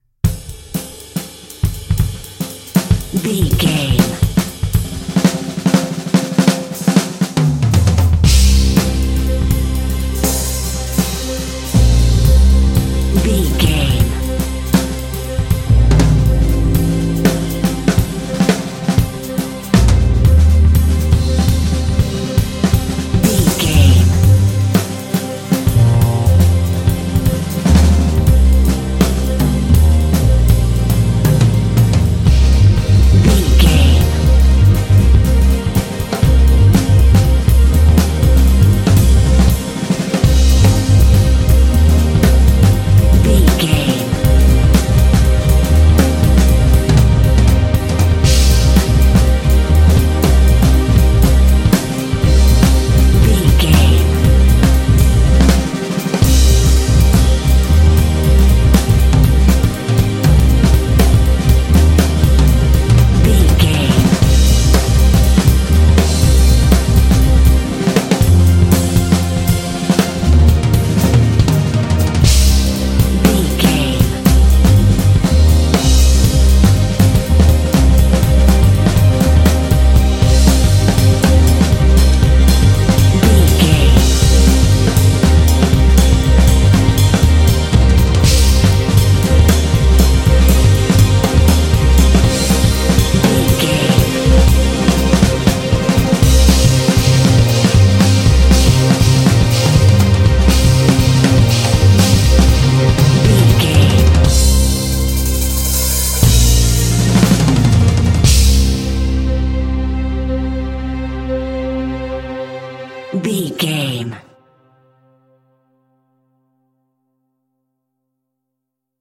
Aeolian/Minor
Fast
groovy
ethereal
dreamy
double bass
strings
jazz
jazz drums
cinematic